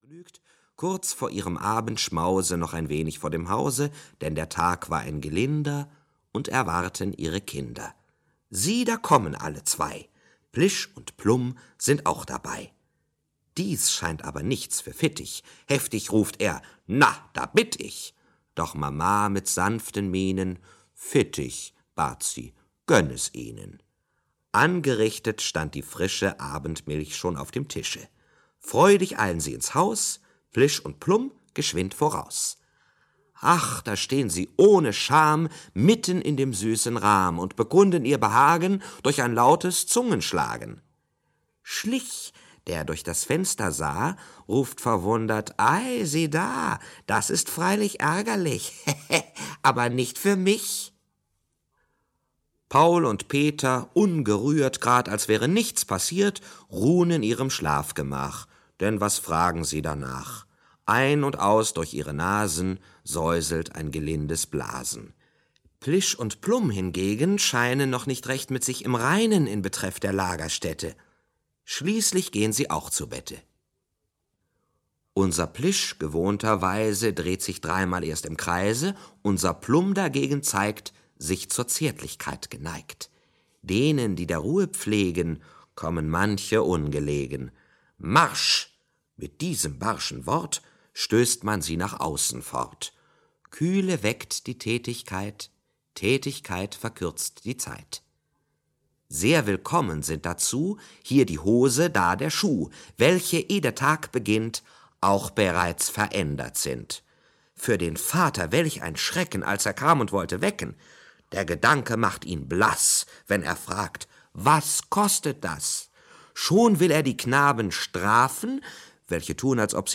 Max und Moritz - Wilhelm Busch - Hörbuch